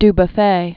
(d-bə-fā, dü-bü-fĕ), Jean 1901-1985.